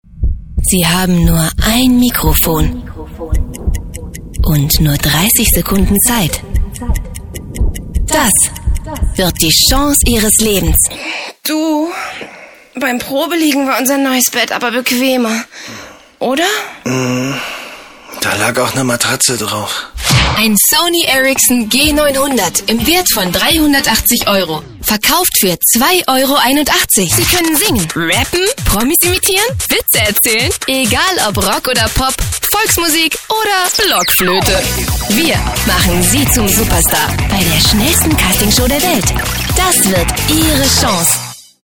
Professionelle Sprecherin und Schauspielerin
Sprechprobe: Werbung (Muttersprache):
german female voice over artist, young voice